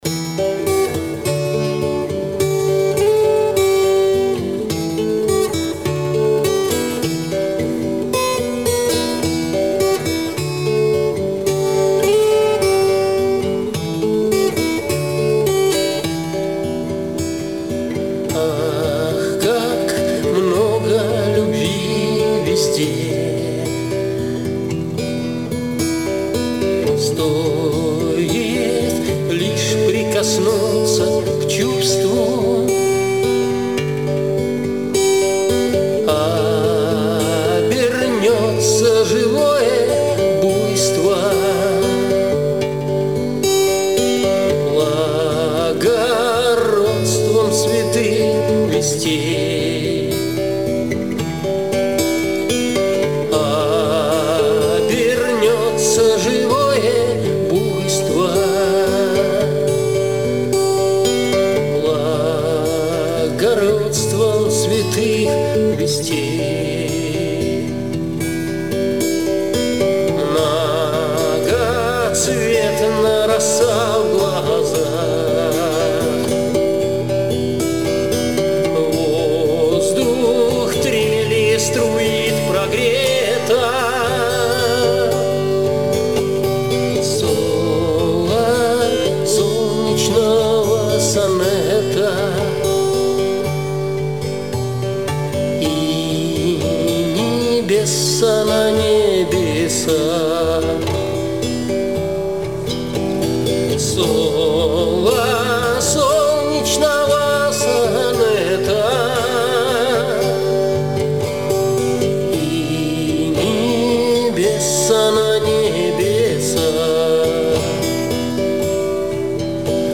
Арт рок Авторские песни